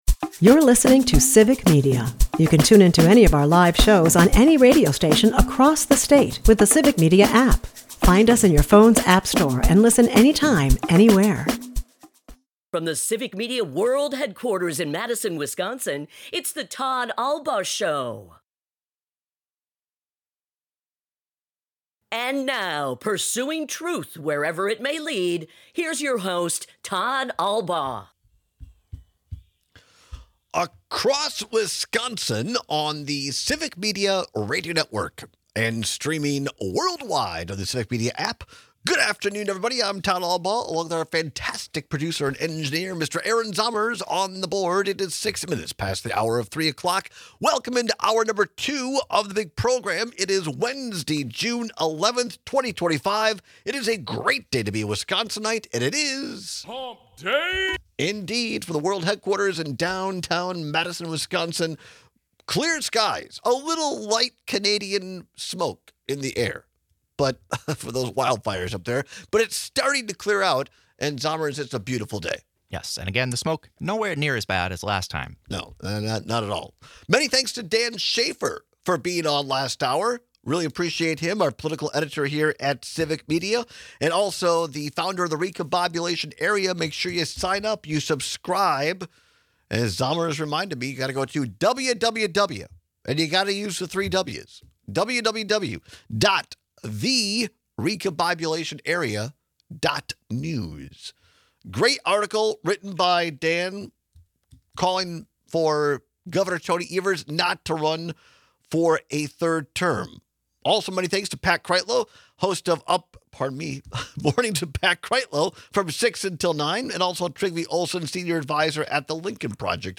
We take some calls and texts with strange funeral stories. At the bottom of the hour, we address the big parade happening this weekend– but wait, it’s not the one you’re thinking of.